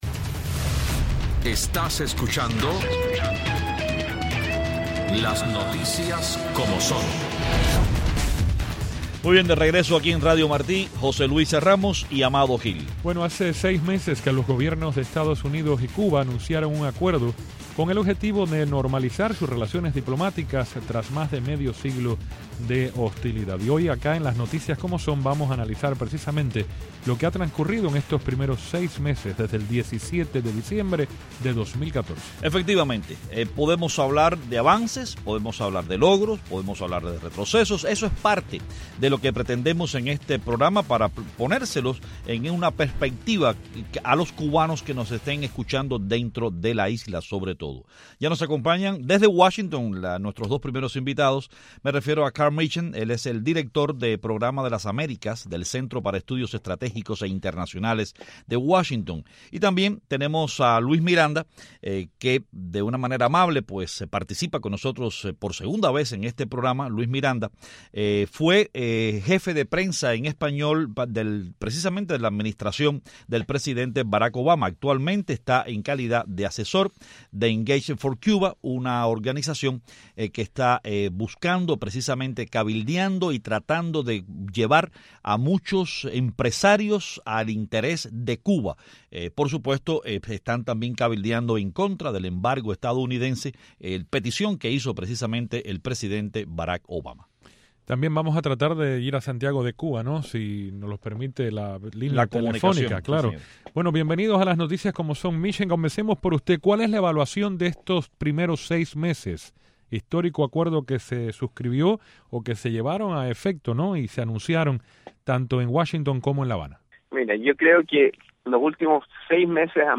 Hace seis meses que los gobiernos de Cuba y Estados Unidos anunciaron su intención de reestablecer relaciones diplomáticas tras más de un medio siglo de hostilidades. Nuestros panelistas analizan que avances o retrocesos ha tenido el proceso.